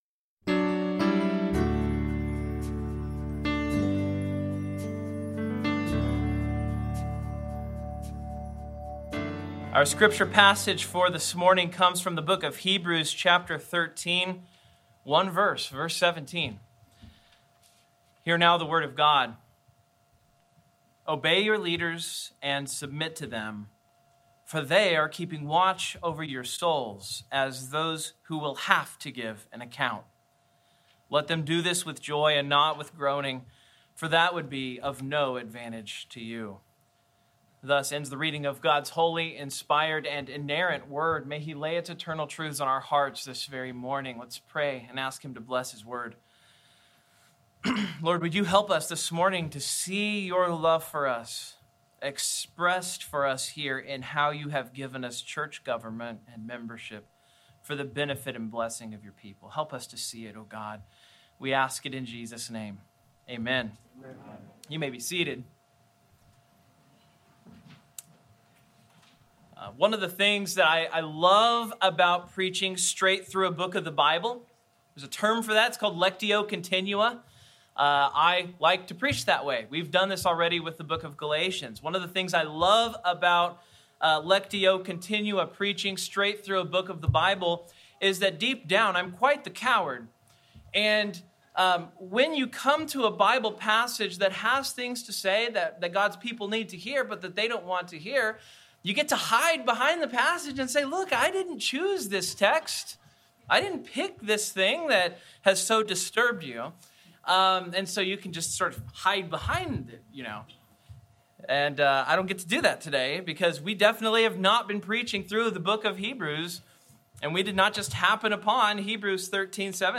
Service Type: Morning Sermon